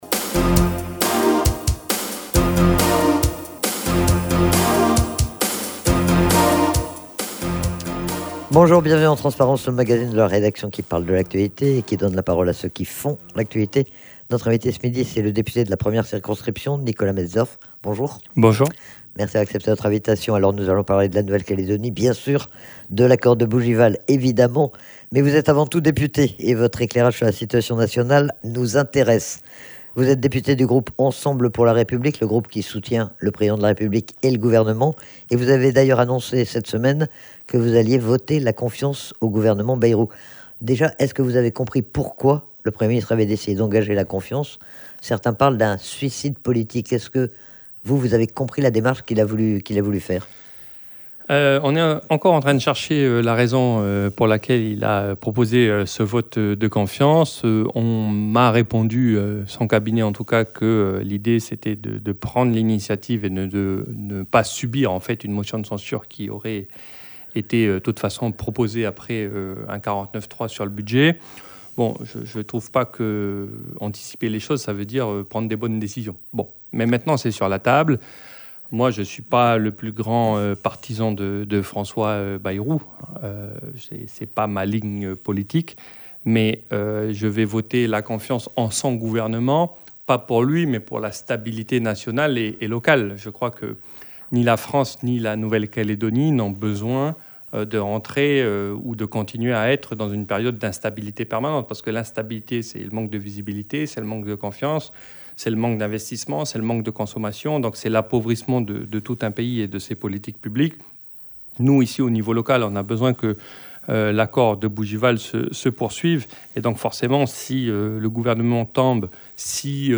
Le député Nicolas Metzdorf était, ce midi, l'invité du magazine Transparence. Il est notamment revenu sur la situation politique nationale, à quelques jours du vote de confiance demandé par le Premier ministre, mais aussi sur les conséquences que cela peut avoir pour la Nouvelle-Calédonie et pour le calendrier de Bougival.